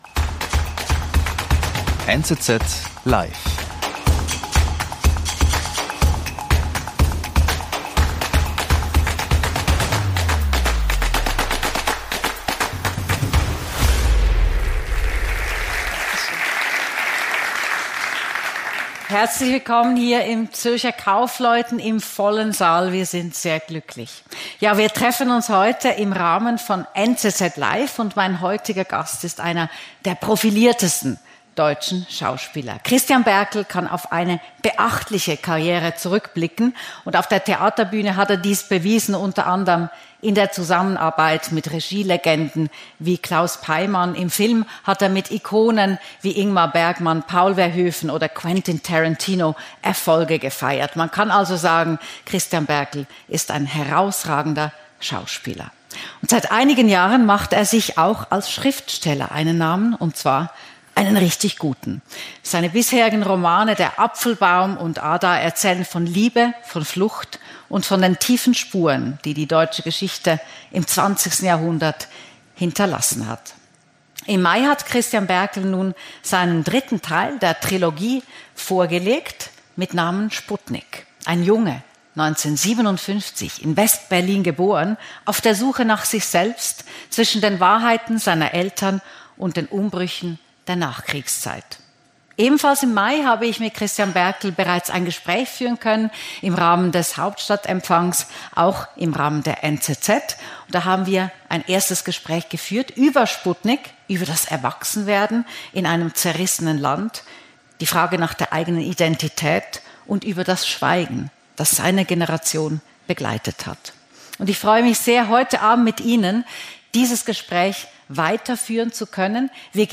Im Gespräch mit der Moderatorin Eva Wannenmacher gewährt er Einblicke in sein Schaffen, seine Kindheit und seine Karriere. Er erzählt von seiner Zeit im Theater und als Schauspieler sowie von Begegnungen mit Hollywood-Grössen.
Ausserdem kommen die Zuhörer in den Genuss von Passagen aus seinem neusten Werk.